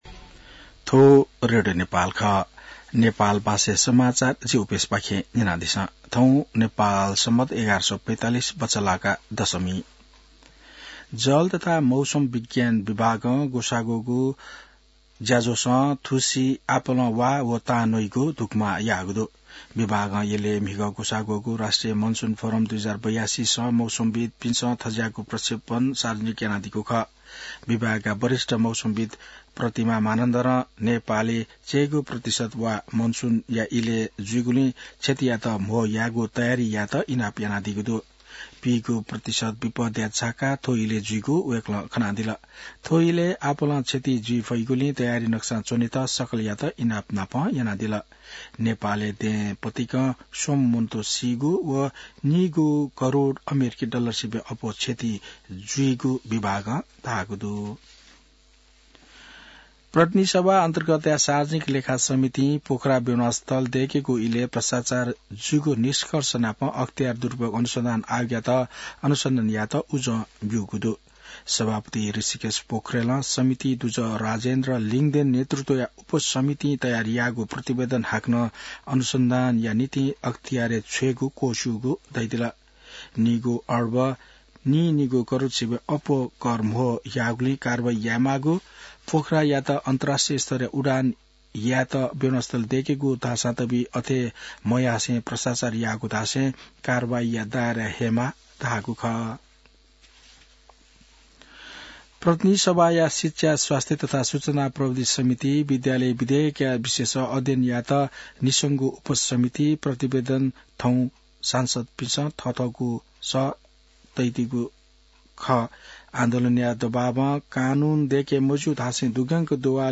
नेपाल भाषामा समाचार : ८ जेठ , २०८२